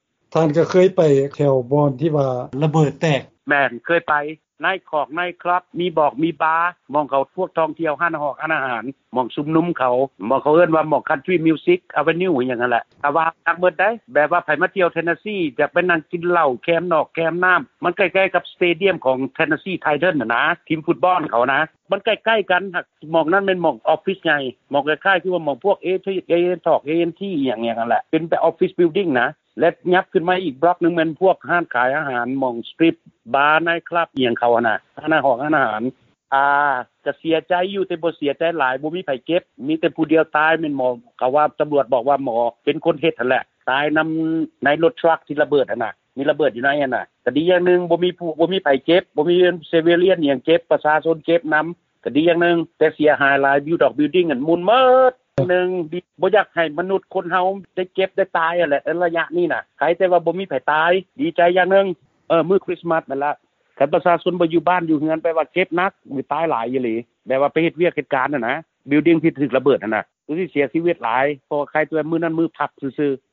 ທີ່ໄດ້ໃຫ້ສຳພາດກ່ຽວກັບເຫດລະເບີດແຕກຢູ່ໃນເມືອງແນສວີລ ລັດເທັນເນສຊີ